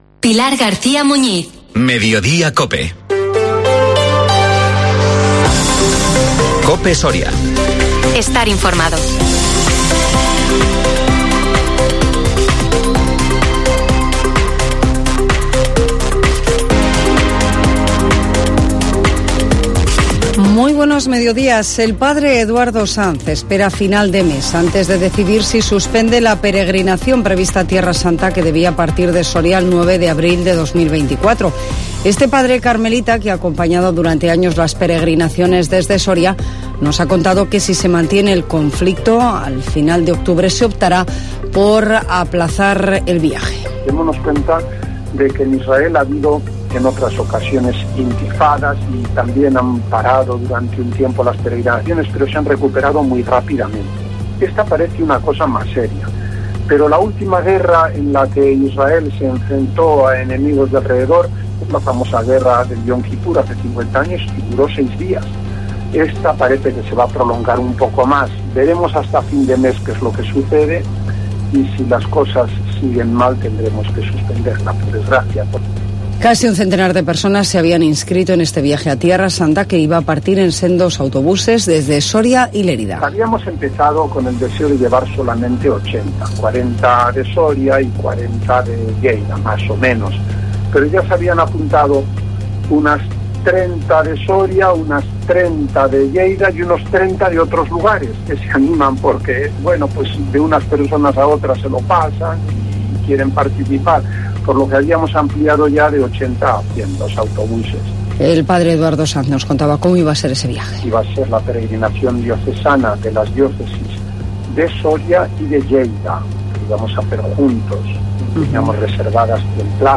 INFORMATIVO MEDIODÍA COPE SORIA LUNES 16 OCTUBRE4 2023